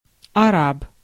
Ääntäminen
Synonyymit арап сарацин мурин агарянин мавр Ääntäminen : IPA: /ɐ.ˈrap/ Haettu sana löytyi näillä lähdekielillä: venäjä Käännöksiä ei löytynyt valitulle kohdekielelle.